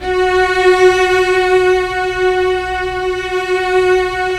Index of /90_sSampleCDs/Roland LCDP13 String Sections/STR_Vcs I/STR_Vcs1 Sym Slo